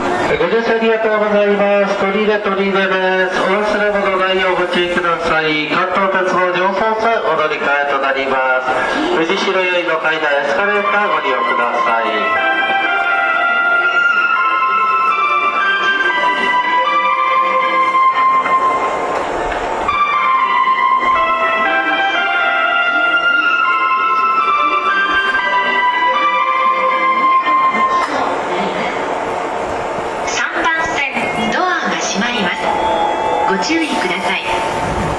曲名不明 サウンドファクトリー製メロディ